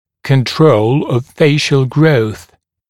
[kən’trəul əv ‘feɪʃl grəuθ][кэн’троул ов ‘фэйшл гроус]контроль черепно-лицевого роста